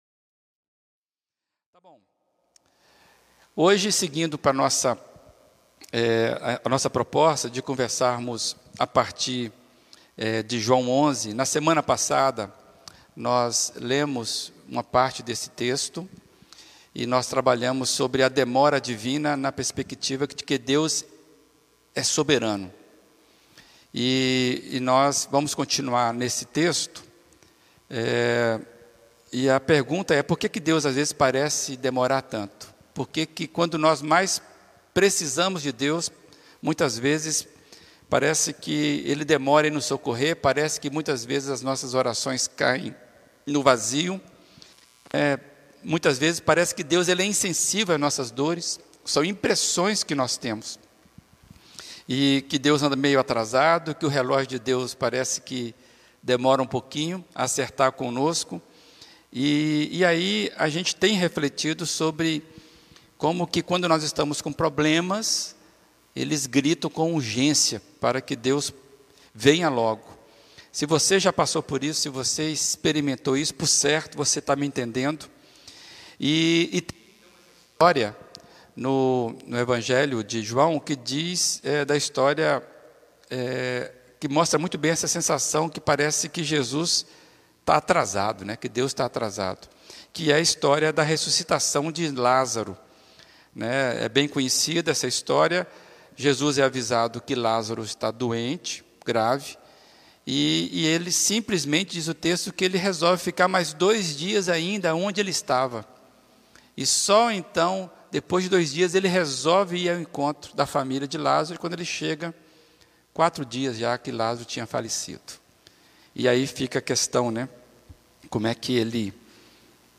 Mensagem